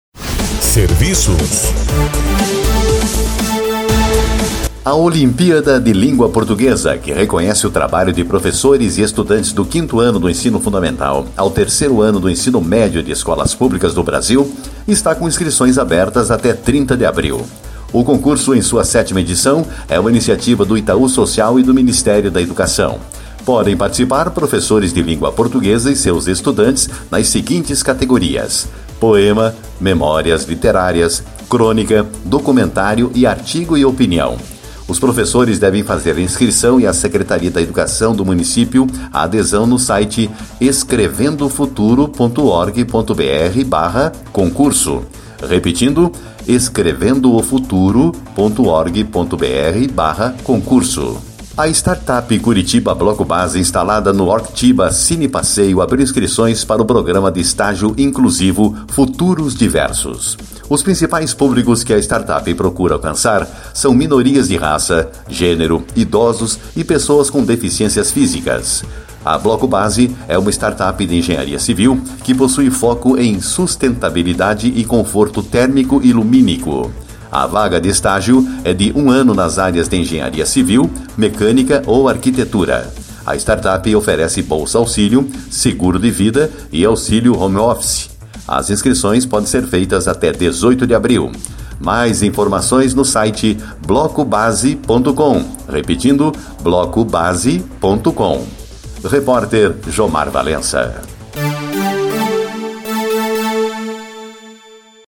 boletim de serviços